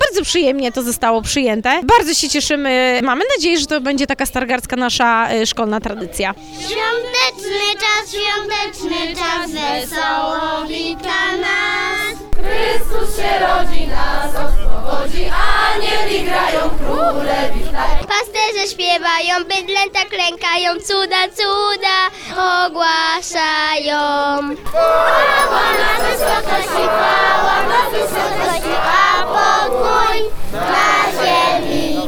Na Rynku Staromiejskim kolędowaliśmy z Mikołajami i Aniołami.
Na placu przed Ratuszem zjawiły się tłumy.
Wśród śpiewających byli zarówno przedszkolaki, uczniowie szkół średnich, jak i mieszkańcy.
kolędowanie na Rynku.mp3